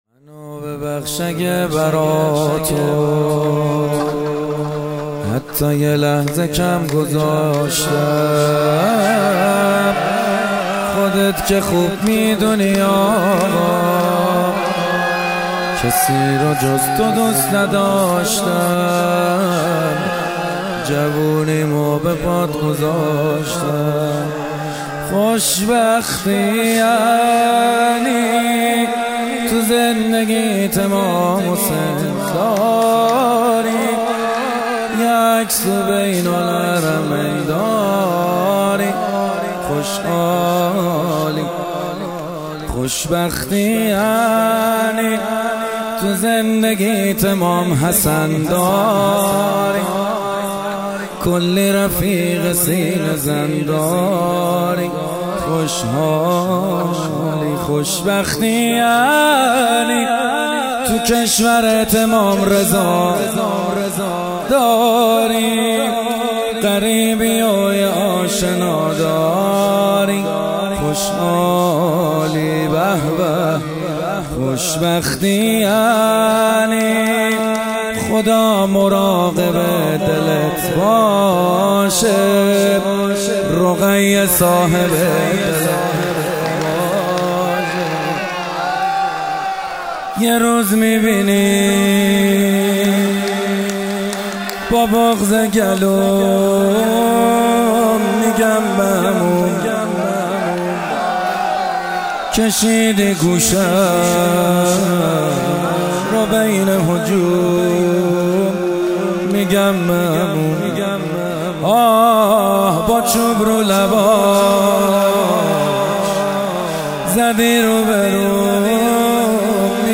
فاطمیه 97 - شب سوم - شور - منو ببخش اگه برا تو
فاطمیه 97 شب سوم شور محمد حسین حدادیان